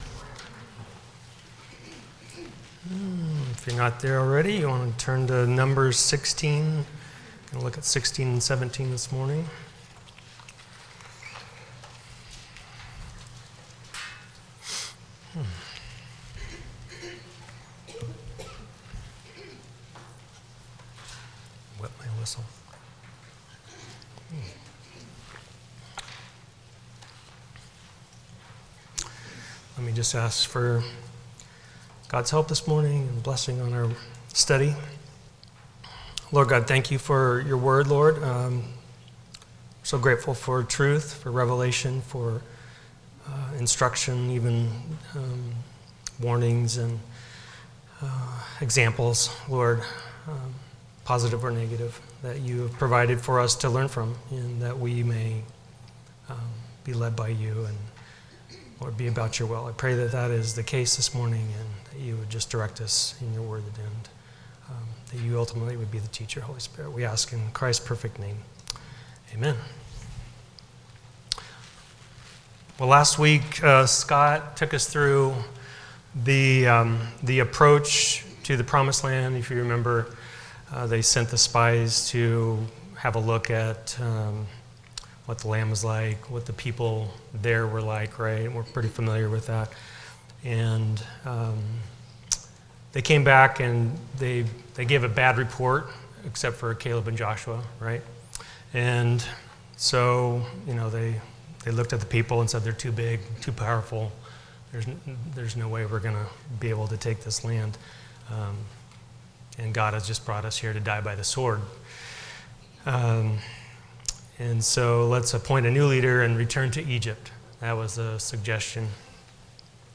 The Exodus Passage: Numbers 16-17 Service Type: Sunday School « The Interludes in the Revelation No. 4